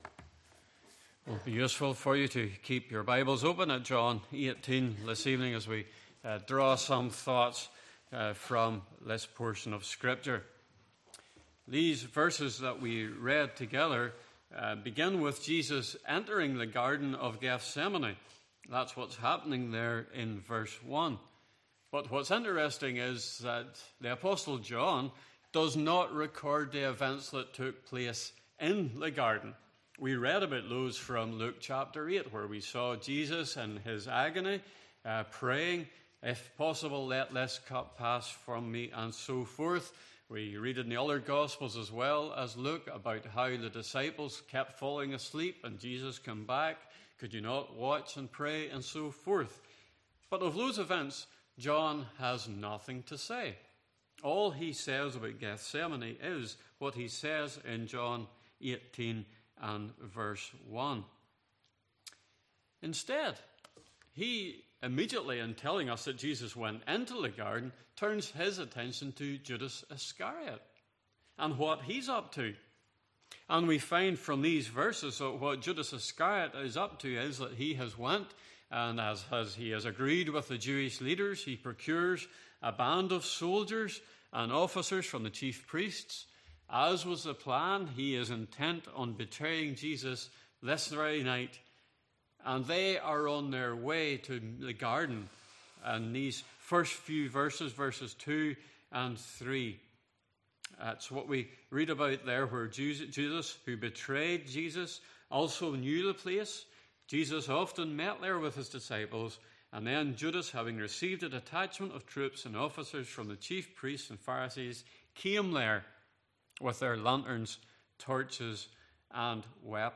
Passage: John 18:1-9 Service Type: Evening Service